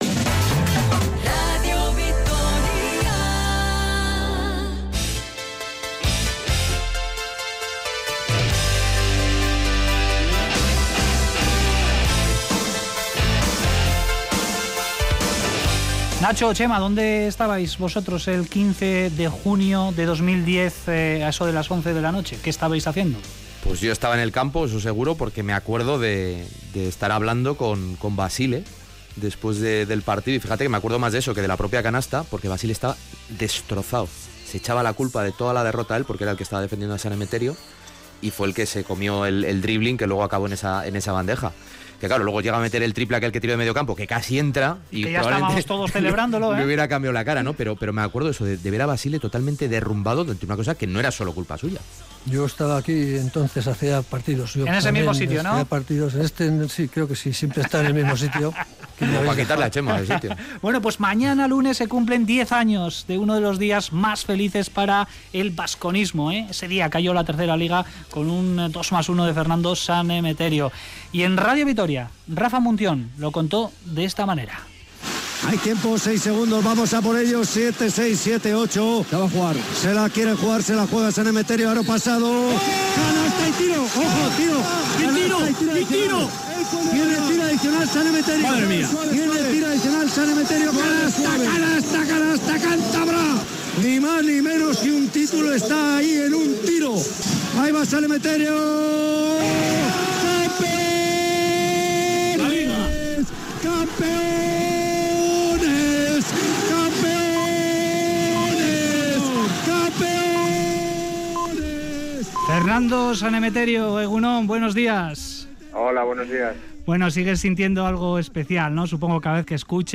Audio: Entrevista exclusiva a Fernando San Emeterio en Radio Vitoria en el X aniversario del 2+1 que dio la tercera Liga al Baskonia el 15 de junio de 2010